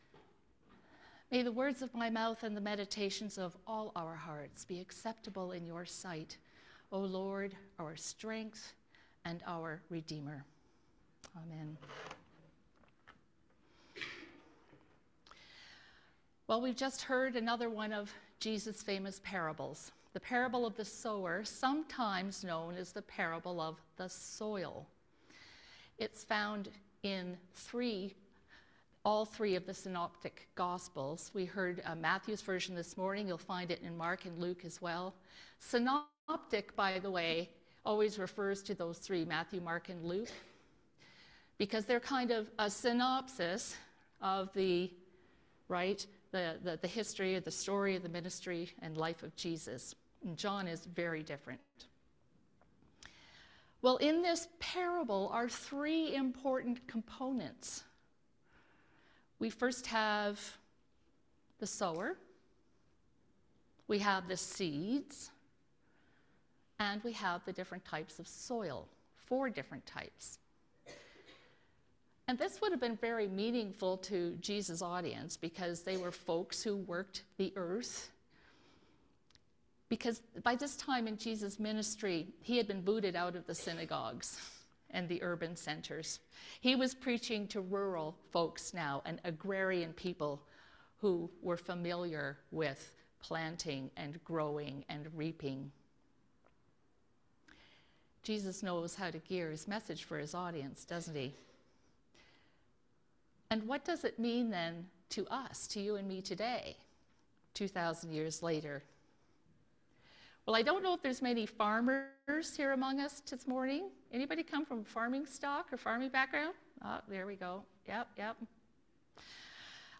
Sermons | St. George's Anglican Church
Please note there is a 15 second loss of audio just after the 6 minute mark during the sermon.